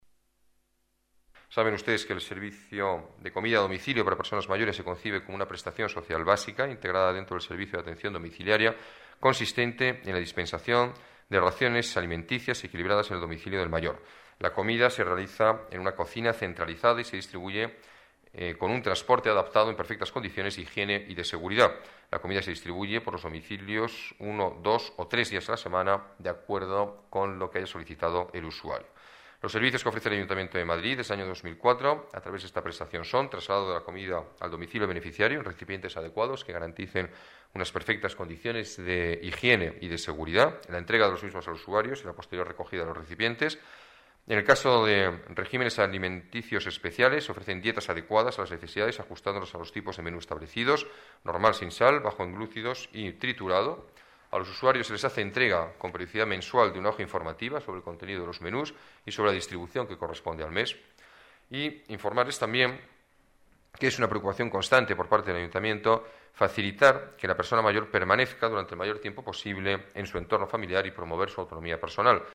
Nueva ventana:Declaraciones del alcalde de Madrid, Alberto Ruiz-Gallardón: Servicio de comidas a domicilio para mayores